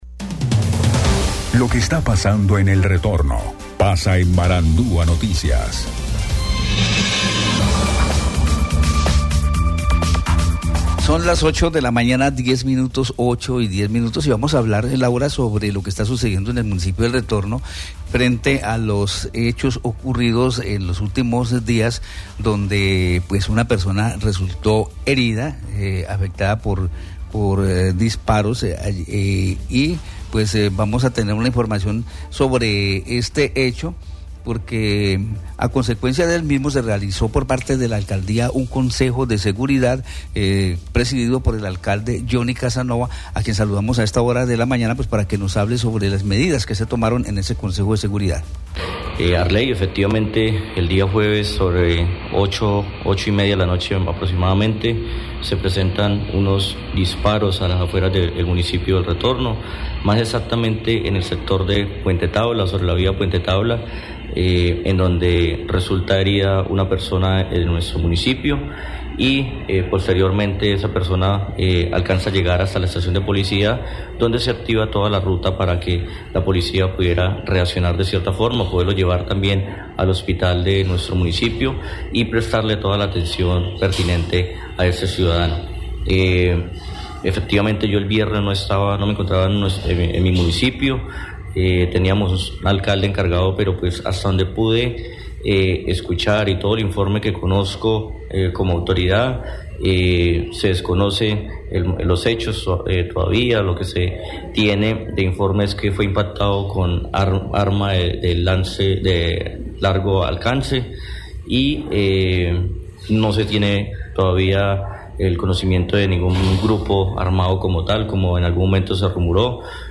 Alcalde de El Retorno, Johnny Casanova, en Marandua Noticias habla sobre las medidas tomadas en Consejo de Seguridad ante los últimos acontecimientos de violencia ocurridos en el municipio de El Retorno en los que una persona resultó herida.